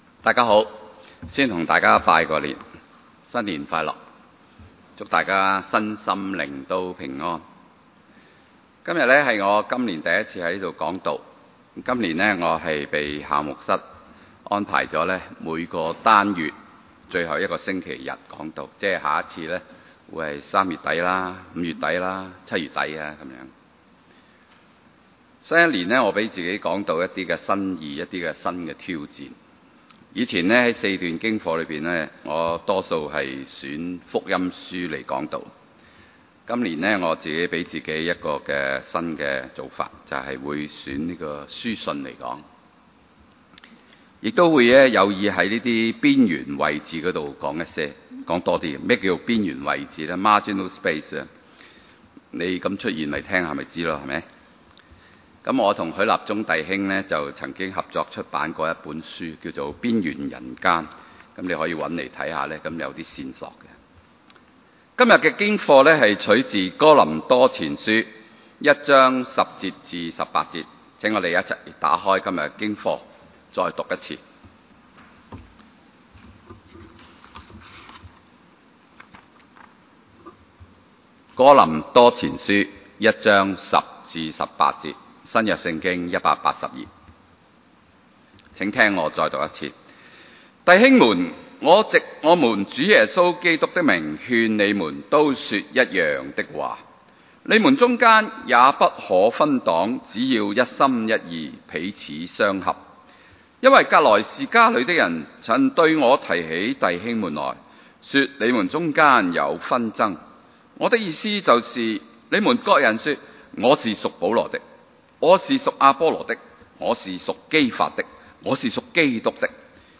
場所：主日崇拜
來源：中文大學崇基學院禮拜堂，謹此鳴謝。